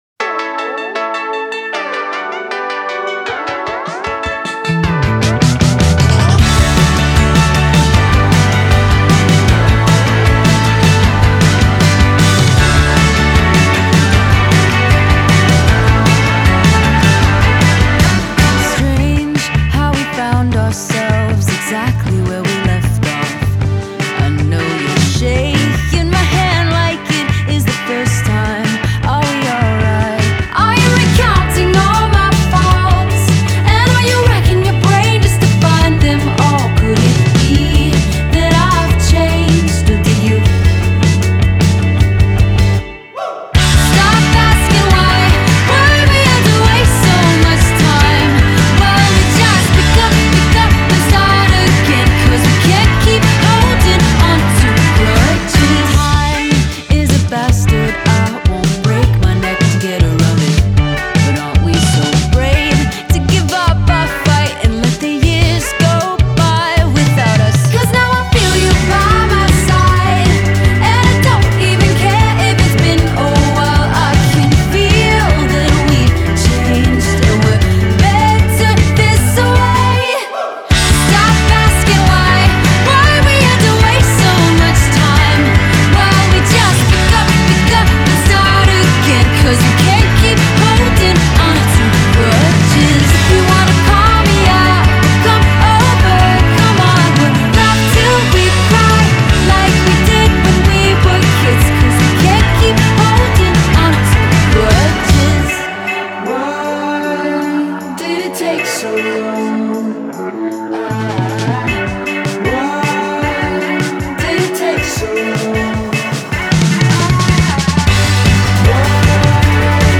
It’s there in the keyboards, it’s there in the vocals